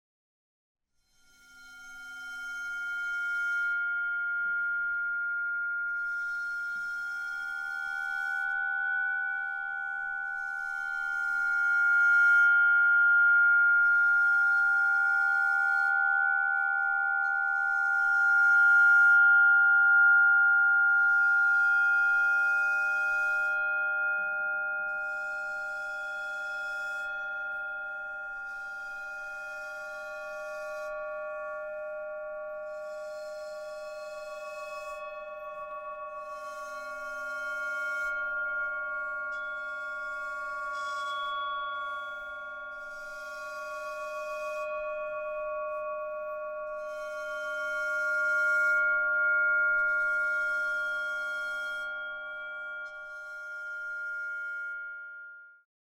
Musik Klangschalen und Planetentöne